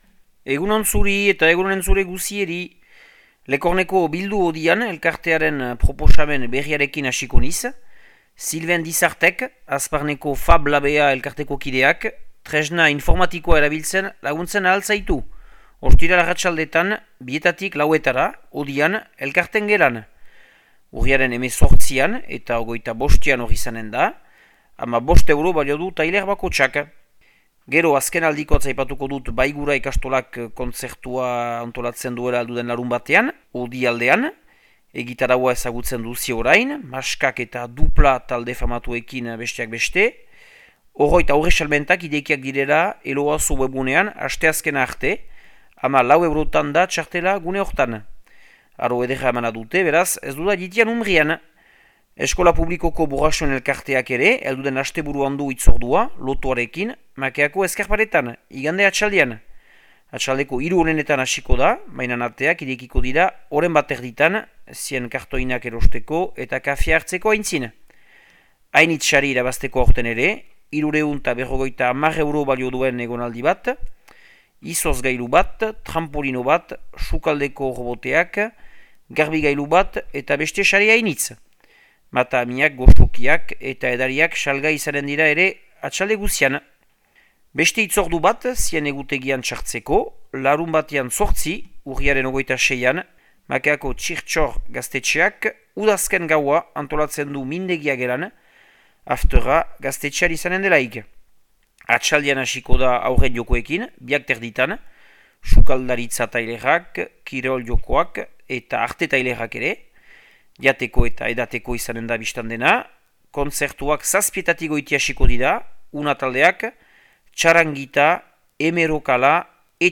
Urriaren 14ko Makea eta Lekorneko berriak